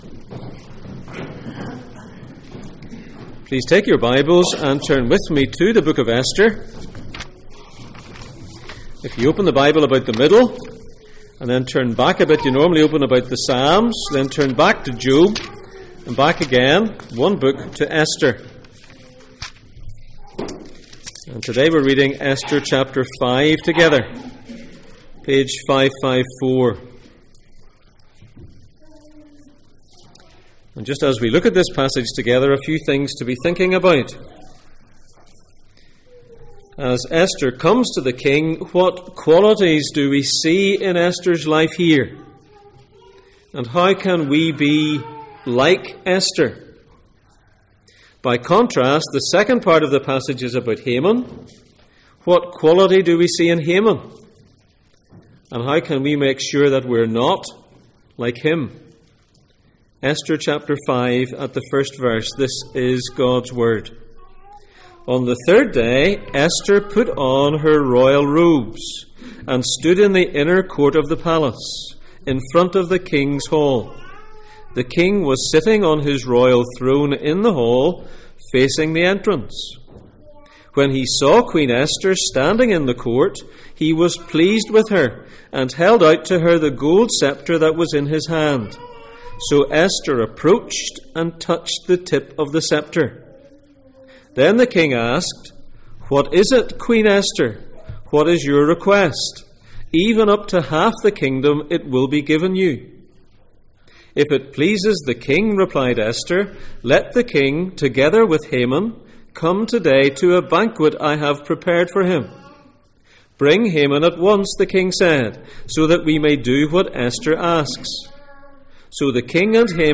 Passage: Esther 5:1-14, Song of Solomon 3:11, Philippians 3:10-11 Service Type: Sunday Morning